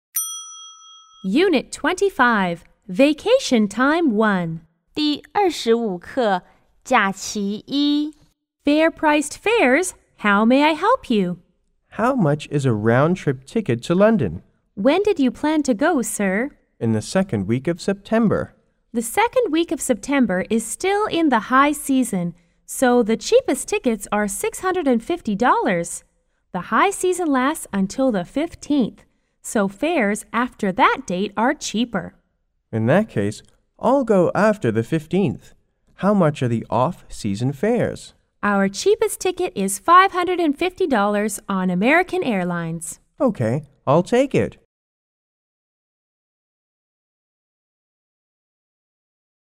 T= Travel Agent C= Caller